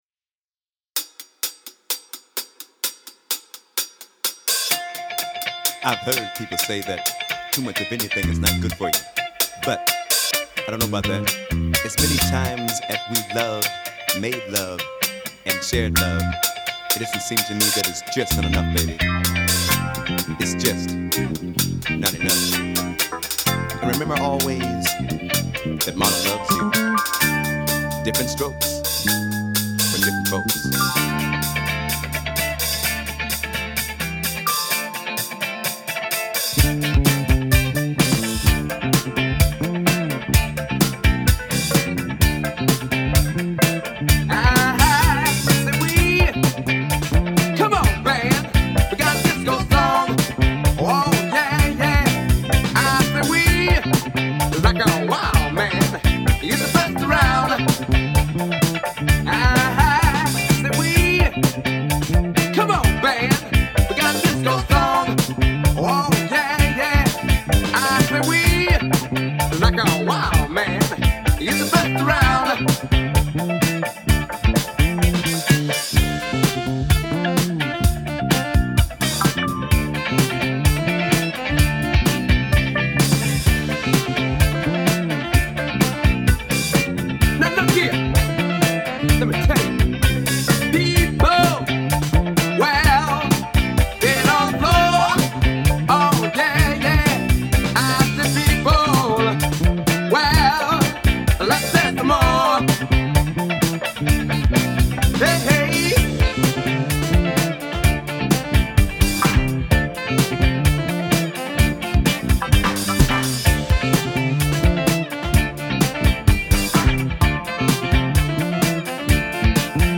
Extended Remix 2026
Extended 128 BpM Remix & Remastered